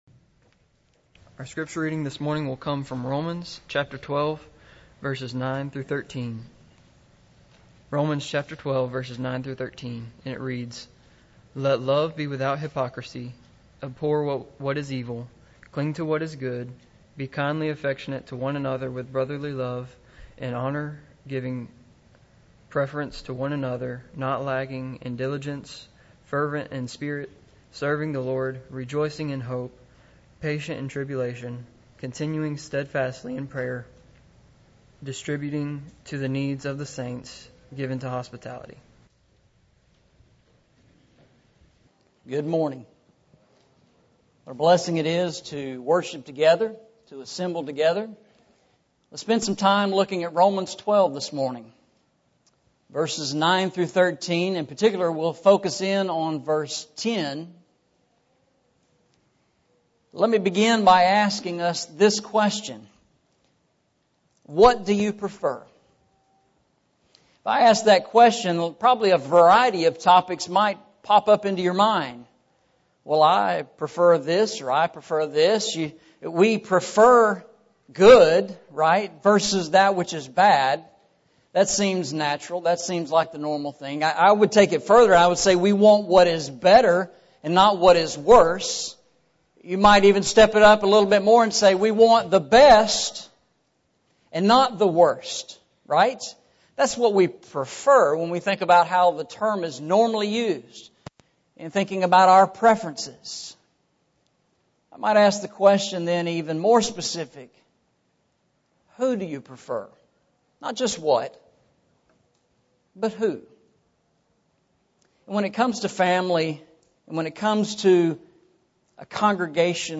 Eastside Sermons Service Type: Sunday Morning Preacher